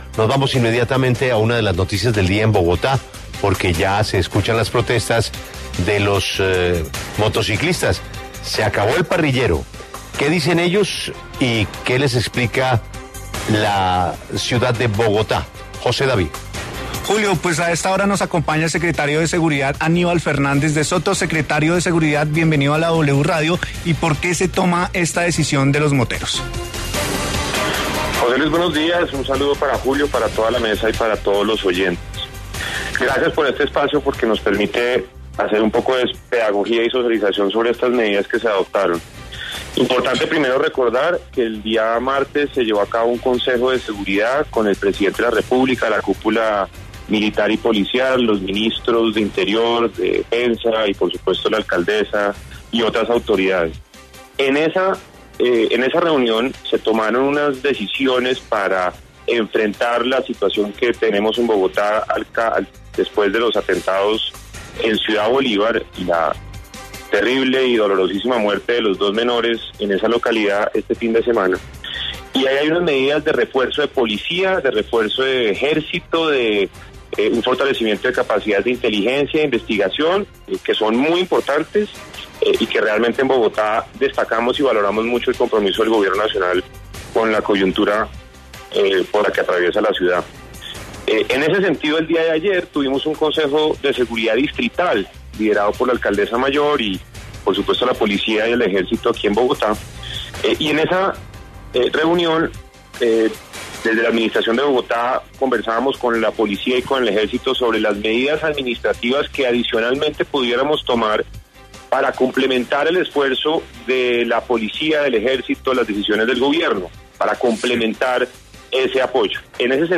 El secretario de Seguridad, Aníbal Fernández, explicó en La W cómo operará la restricción de parrillero en moto en Bogotá para combatir la inseguridad.